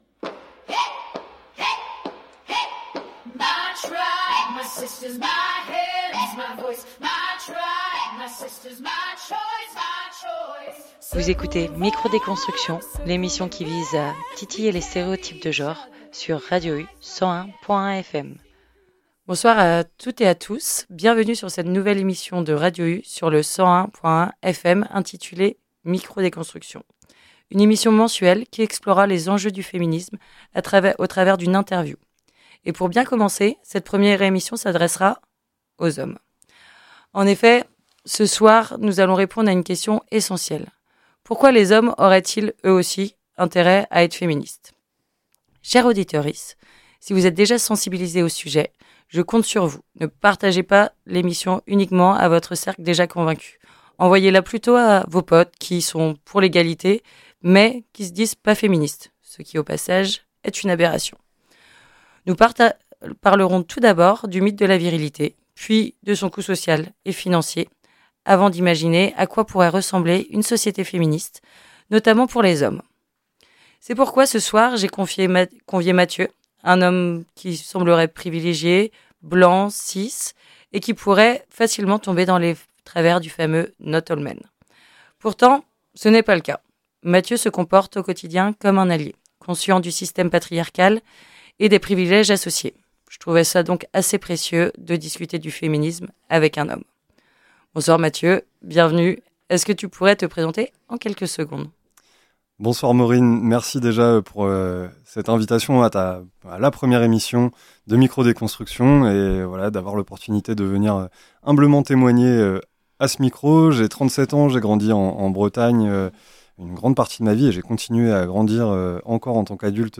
Micro Déconstruction est une nouvelle émission mensuelle qui explorera les enjeux du féminisme au travers d’une interview